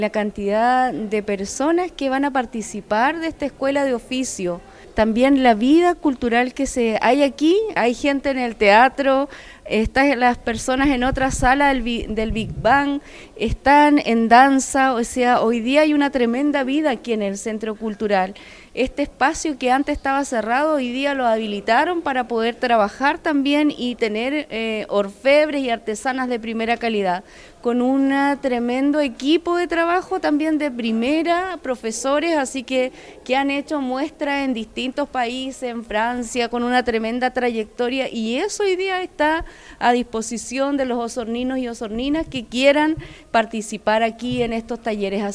Del mismo modo, la Concejala Herta Licán destacó el trabajo que se ha desarrollado en la Corporación Cultural, pues gracias a esto distintas agrupaciones pueden realizar diversas actividades.